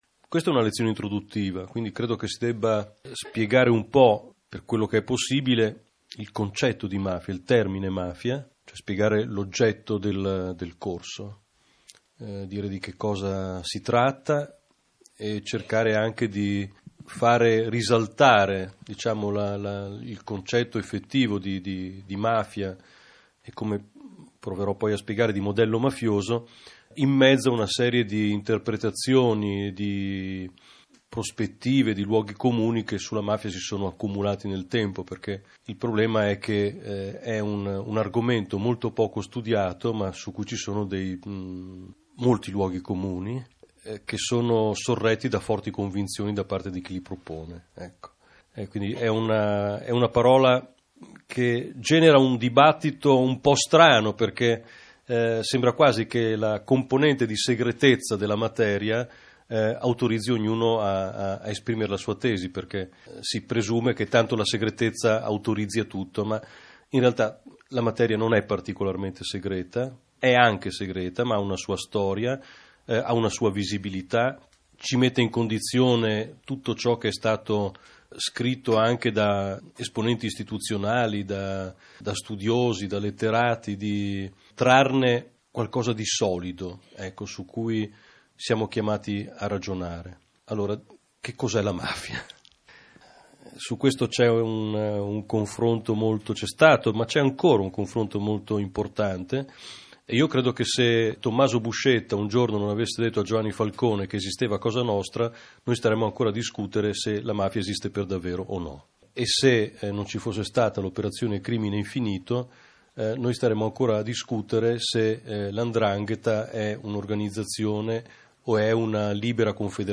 La lezione tenuta da Nando dalla Chiesa, e che ascolterete in versione integrale, si è svolta nell'Auditorium di Radio Popolare il 26 ottobre 2015.